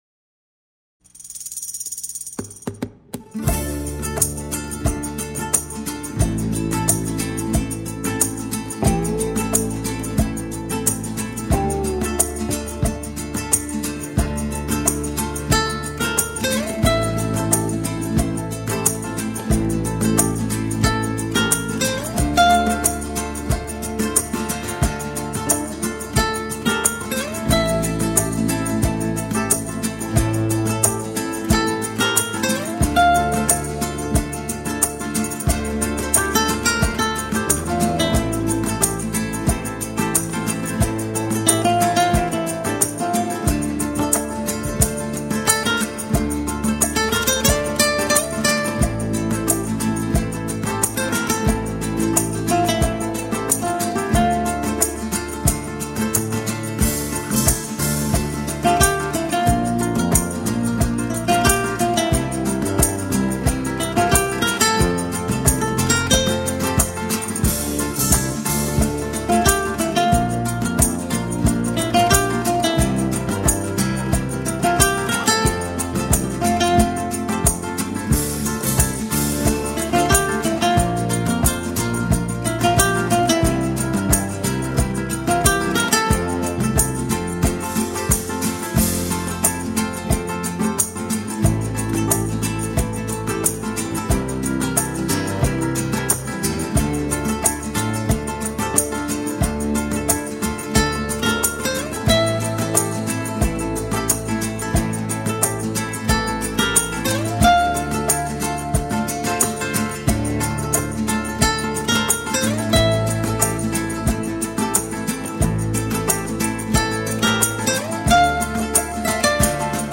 سبک آرامش بخش , عاشقانه , موسیقی بی کلام